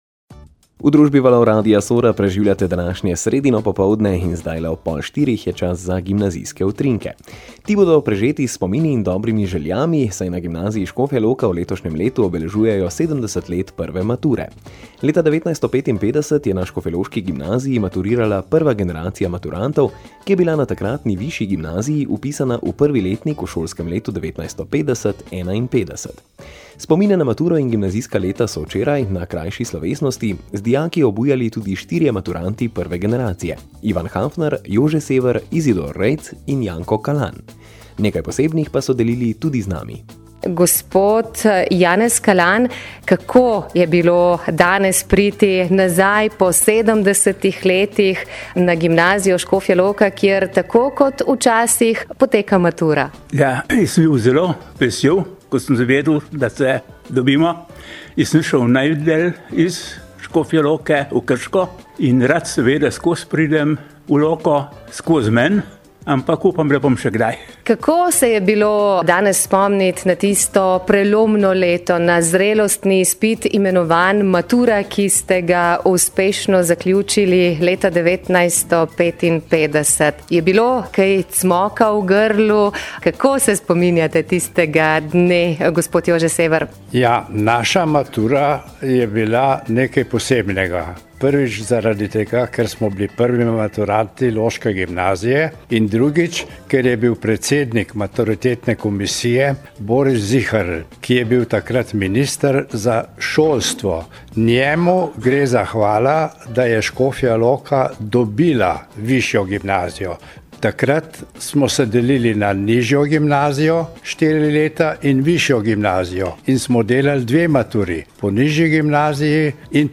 Posnetku lahko prisluhnete na spodnji povezavi povezavi: Posnetek iz programa Gimnazijski utrinki na Radiu Sora: ob 70. obletnici prve mature na škofjeloški gimnaziji Potem je prve maturante naše šole sprejel tudi župan mesta Škofja Loka g. Tine Radinja.